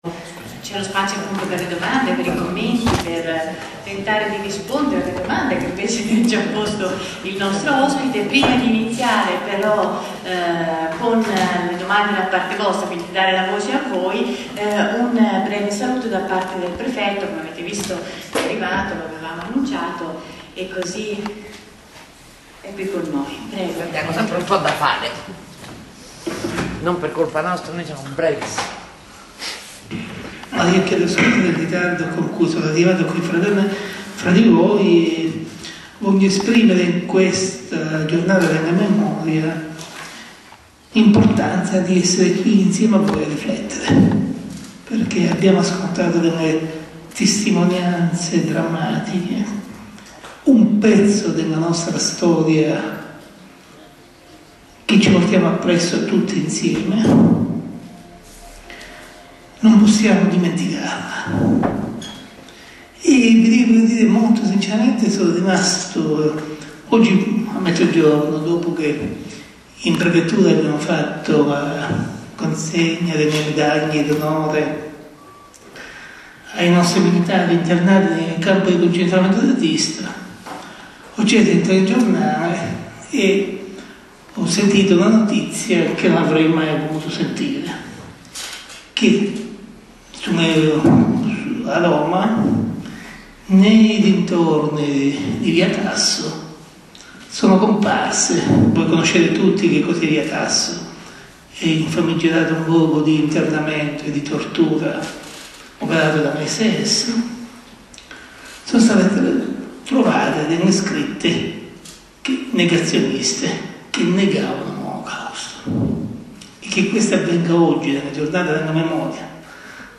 4-prefetto-dibattito.mp3